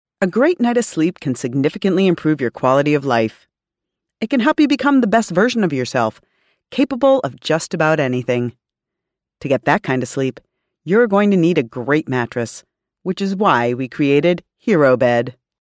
西班牙语配音
• 女西102T 美式英语女声 Artist-2-2 低沉|激情激昂|大气浑厚磁性|沉稳|娓娓道来|科技感|积极向上|时尚活力|神秘性感|调性走心|亲切甜美|感人煽情|素人